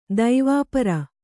♪ daivāpara